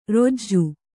♪ rojju